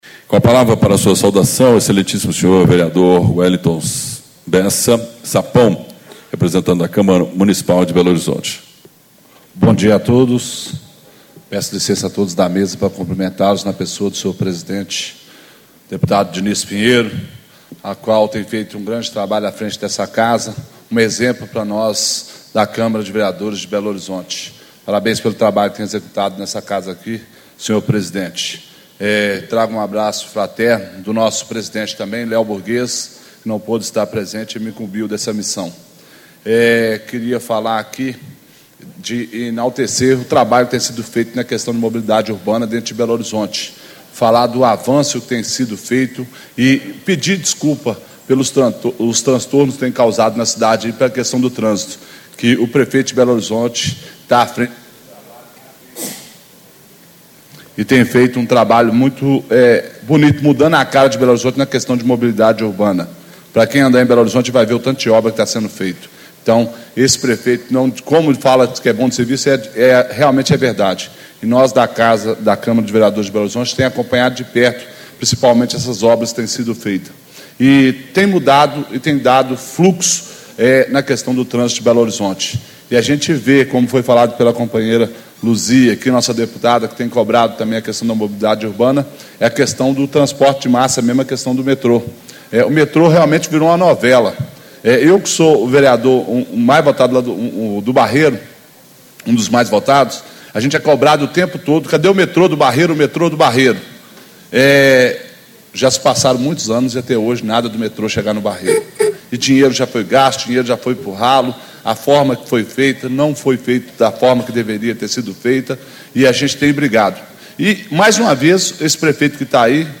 Abertura - Vereador Wellington Bessa 'Sapão' - Representante da Câmara Municipal de Belo Horizonte
Discursos e Palestras